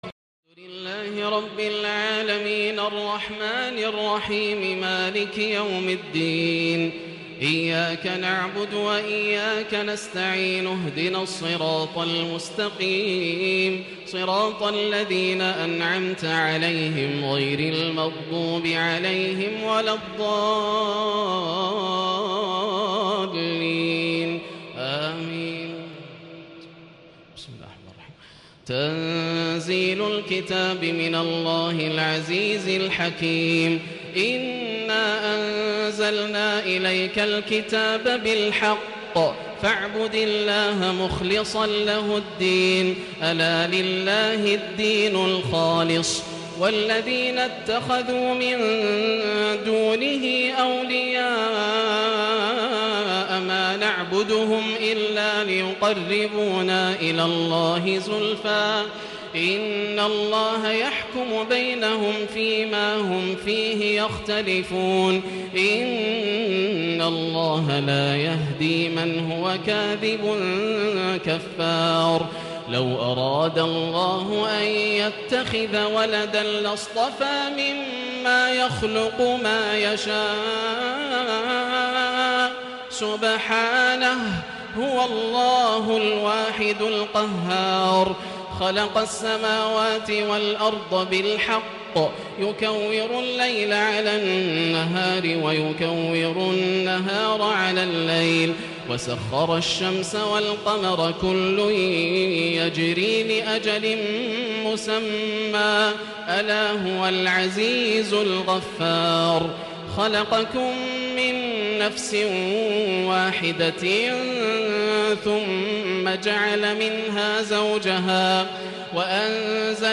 (أمن هو قانت آناء الليل) > الروائع > رمضان 1437هـ > التراويح - تلاوات ياسر الدوسري